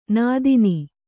♪ nādini